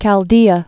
(kăl-dēə)